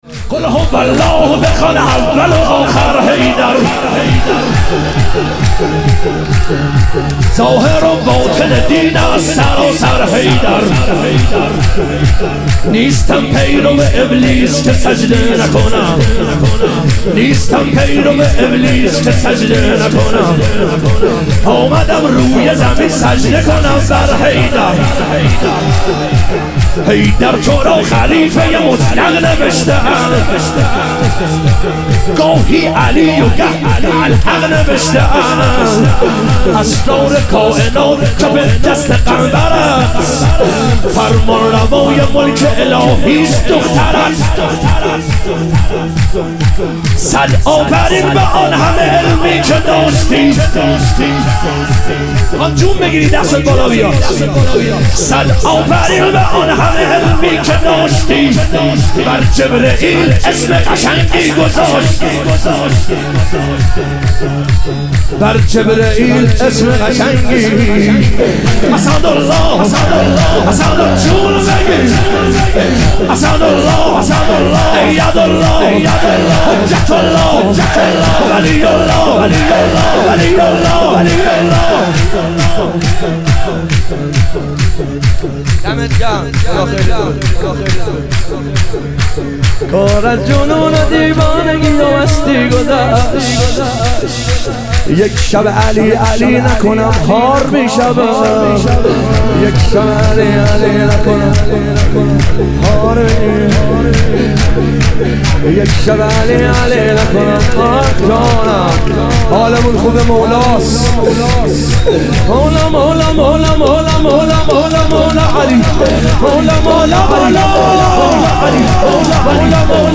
ظهور وجود مقدس حضرت عباس علیه السلام - شور